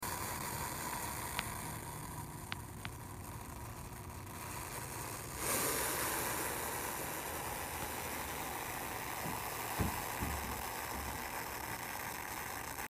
The Sound Of A Saw Scaled Sound Effects Free Download
The sound of a saw-scaled viper rubbing its scales together is pretty wild!